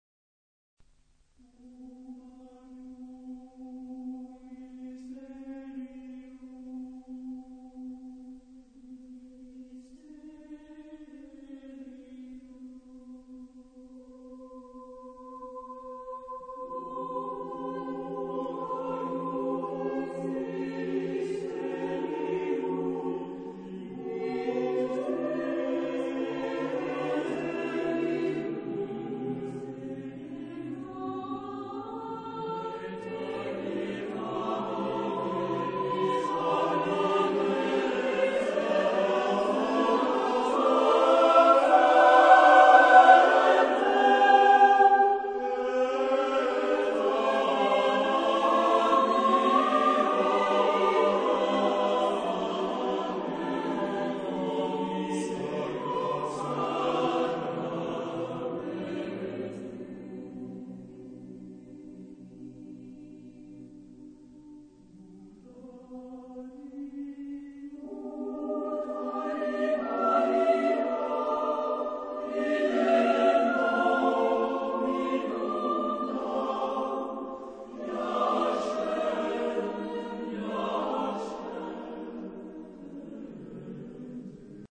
Genre-Style-Forme : Motet ; Sacré
Type de choeur : SATB  (4 voix mixtes )
Tonalité : fa mineur